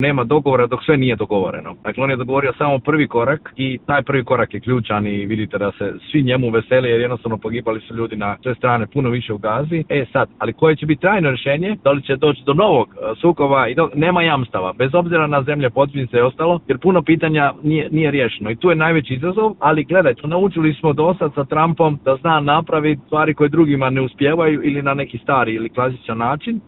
O tome smo u Intervjuu Media servisa razgovarali s komunikacijskim stručnjakom